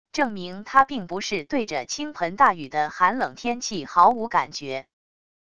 证明她并不是对着倾盆大雨的寒冷天气毫无感觉wav音频生成系统WAV Audio Player